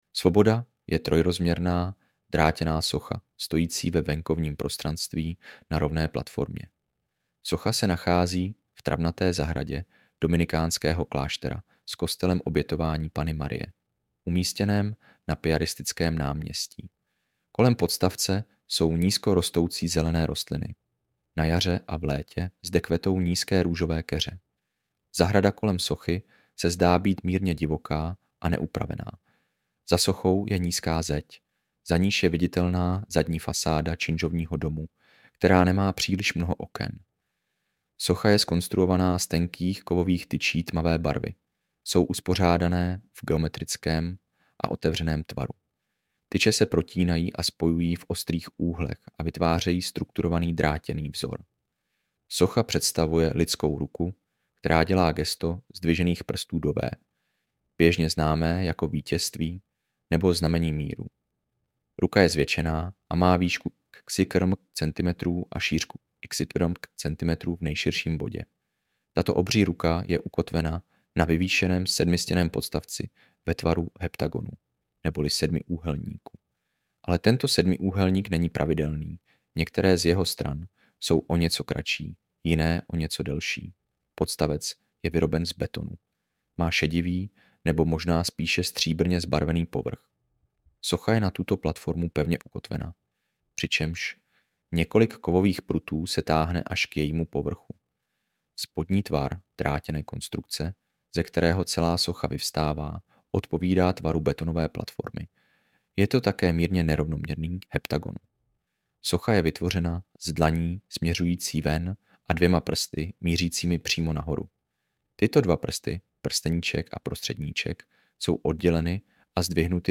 AUDIOPOPIS